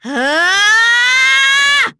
Nicky-Vox_Casting3.wav